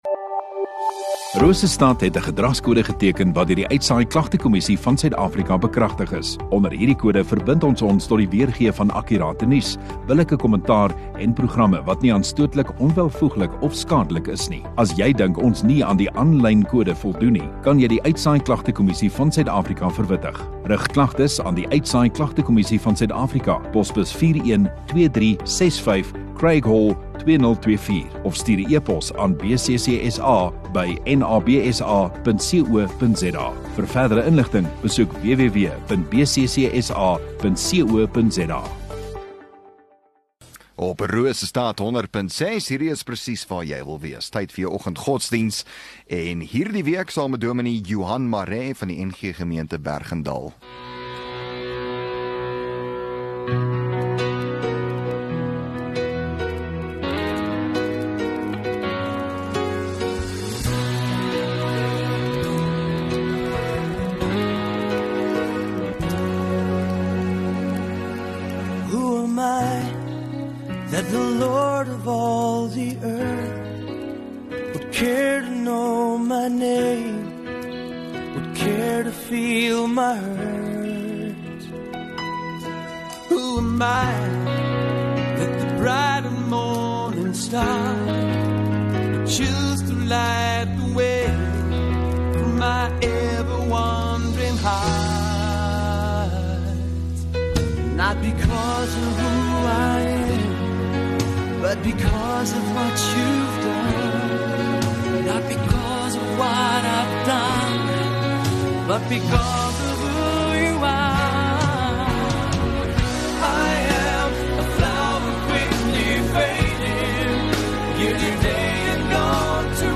12 Jun Donderdag Oggenddiens